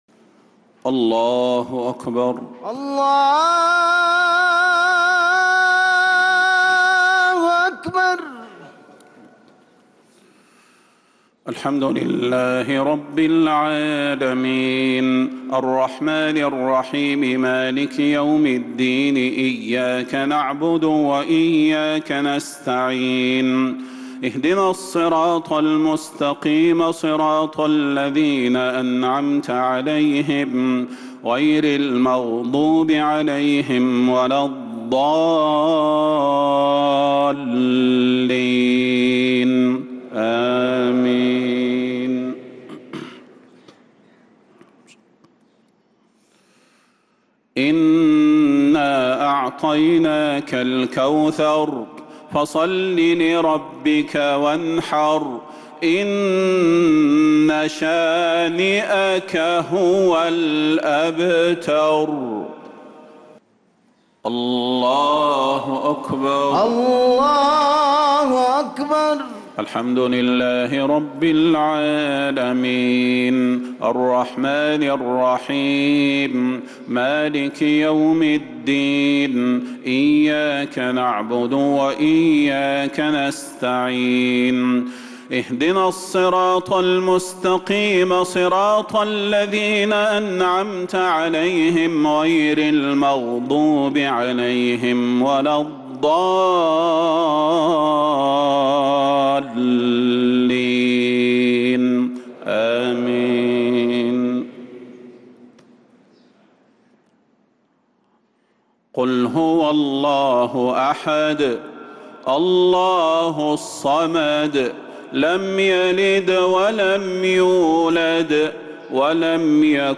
صلاة الجمعة 9 محرم 1447هـ سورتي الكوثر و الإخلاص كاملة | Jumu’ah prayer from Surah al-Kauthar & al-Ikhlas 4-7-2025 > 1447 🕌 > الفروض - تلاوات الحرمين